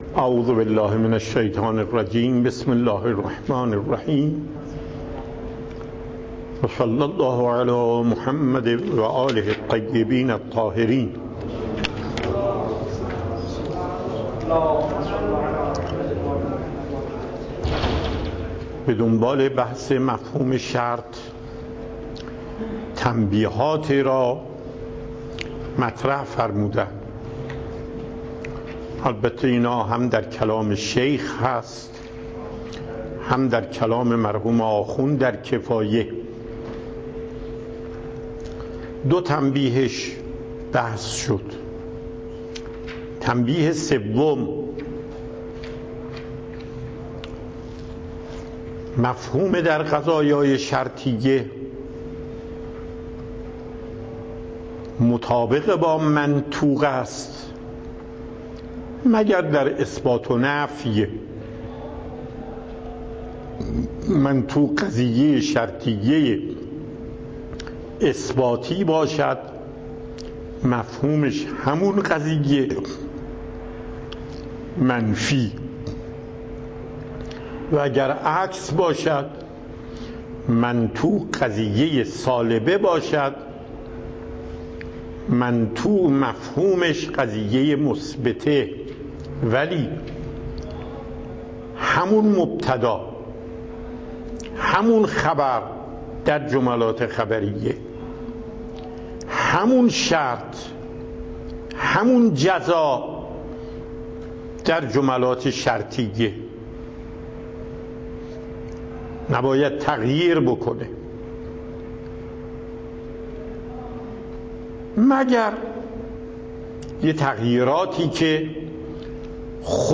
موضوعات : 98-99 , ماه آبان , مفاهیم برچسب‌ها : آیت الله سید علی محقق داماد , آیت الله محقق داماد , اصول , ایت الله سید علی محقق داماد , درس اصول آیت الله محقق داماد , دروس اصول آیت الله محقق داماد , محقق داماد